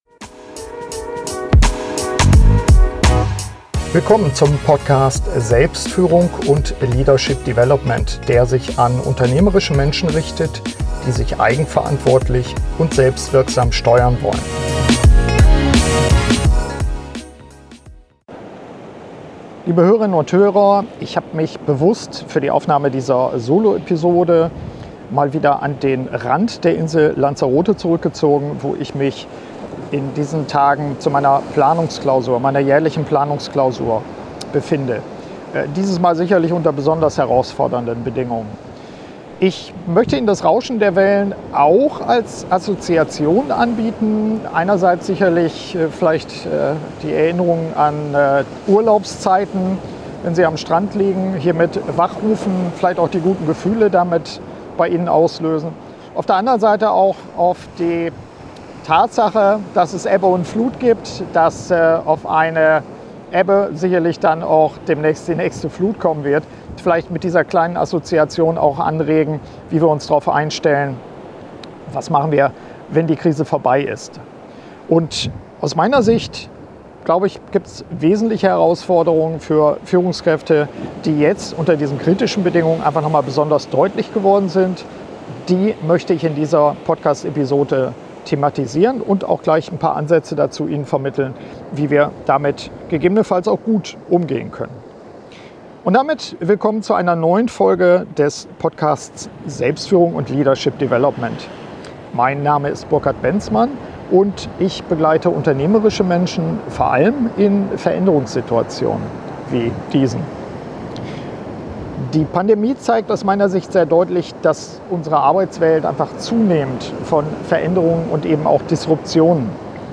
Ich habe sechs wesentliche Herausforderungen ausgewählt. Darüber und über die Möglichkeiten uns selbst zu stärken und zu entwickeln, will ich in dieser Soloepisode sprechen.